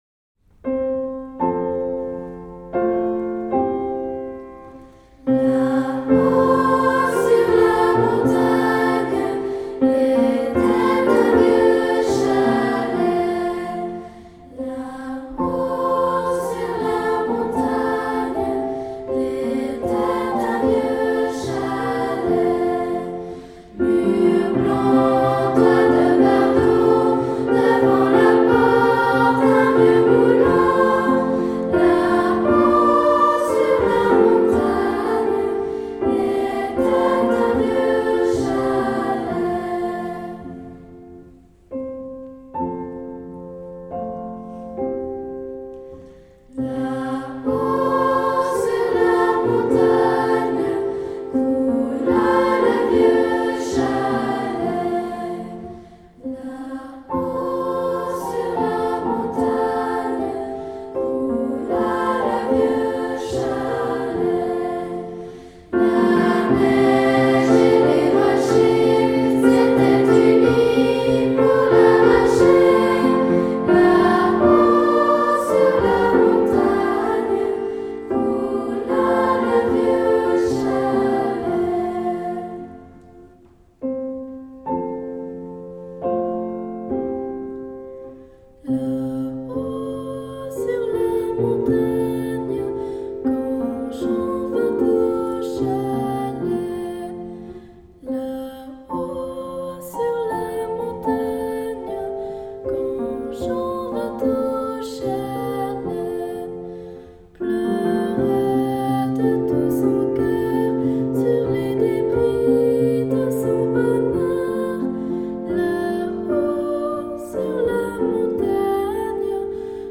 Le vieux chalet (version enfants) | Commune de Sâles
par Le Petit Choeur d'Ecuvillens-Posieux